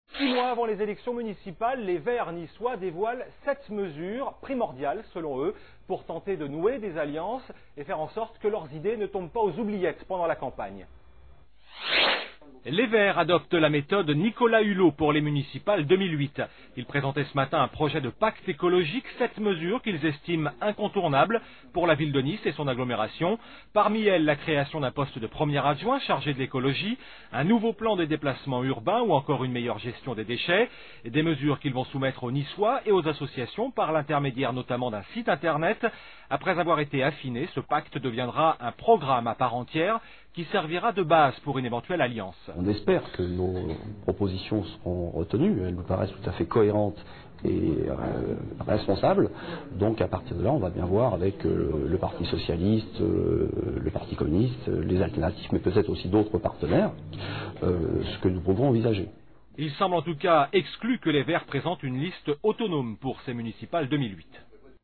Un extrait du JT de france 3 du 11 octobre 2007 :